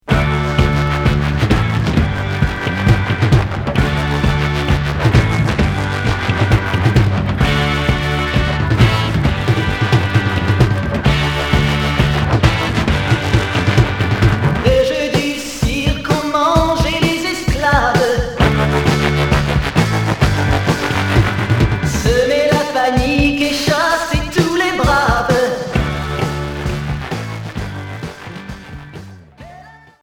Glam